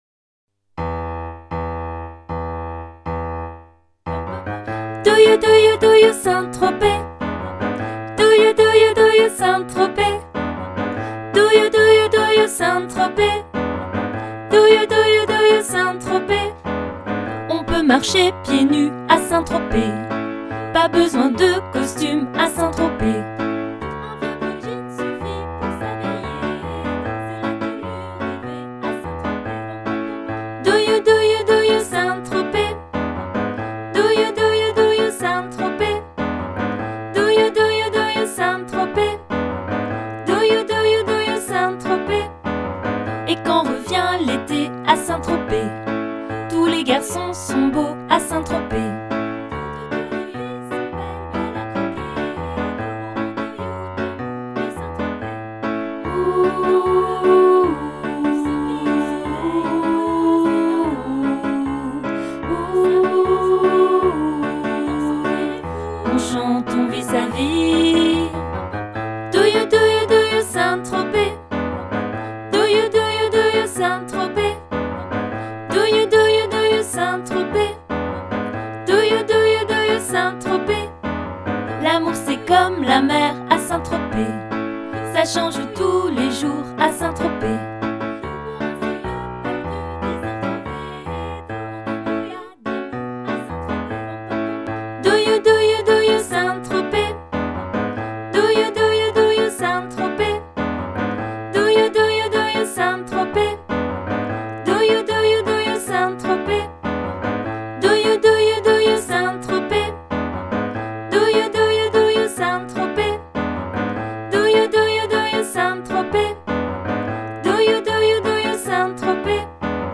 Alti